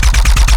GUNAuto_RPU1 B Loop_02_SFRMS_SCIWPNS.wav